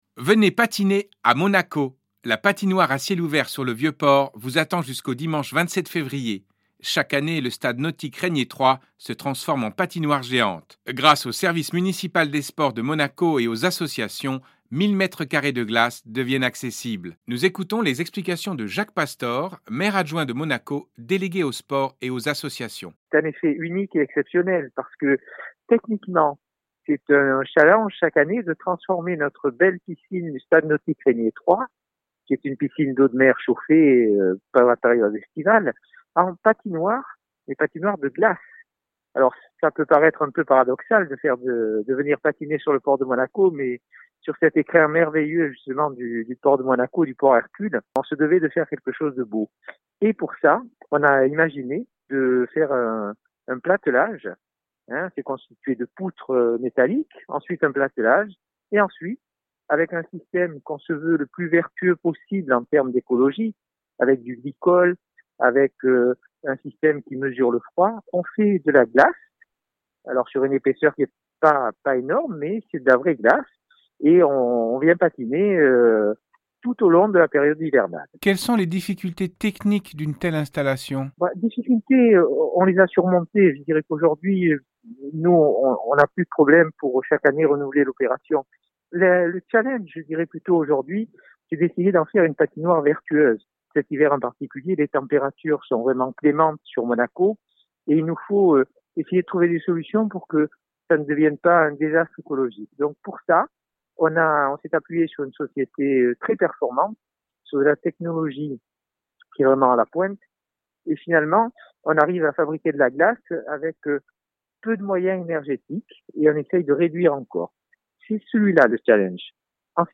Jacques Pastor est Maire Adjoint délégué aux Sports et aux Associations , il est l'invité culturel.